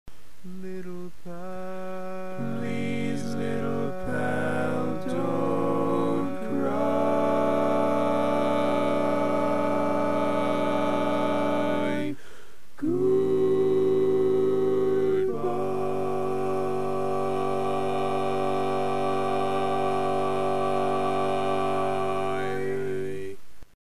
Key written in: G Major
How many parts: 4
Type: Barbershop
Learning tracks sung by